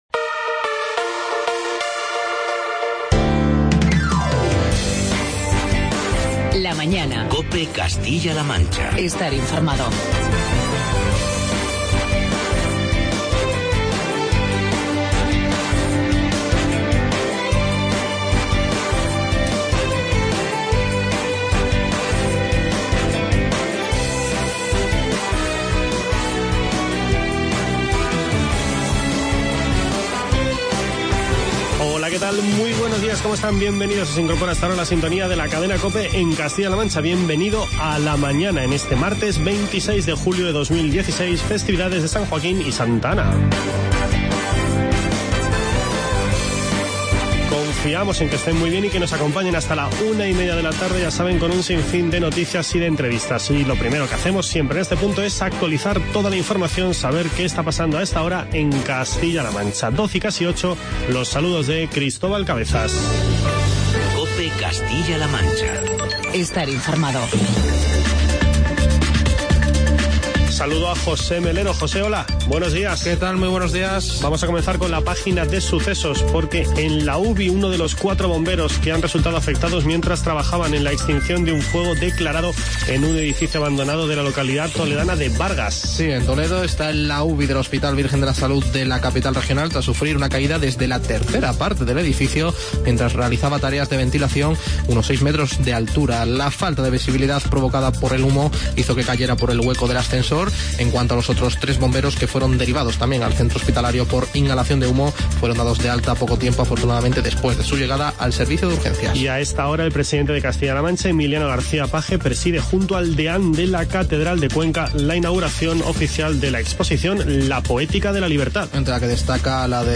Charlamos con el alcalde de Seseña, Carlos Velázquez.